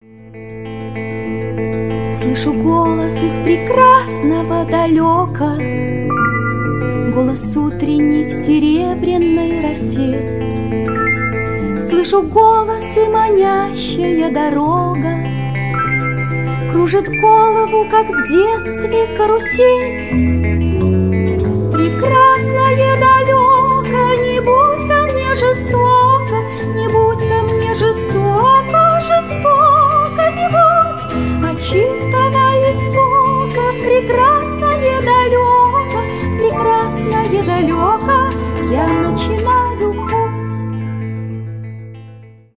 красивый женский голос
детская музыка
красивый вокал